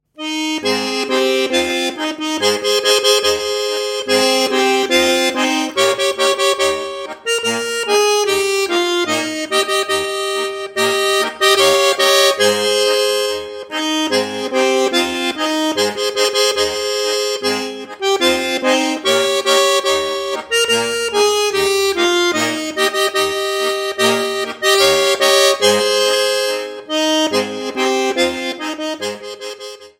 Besetzung: Schwyzerörgeli mit CD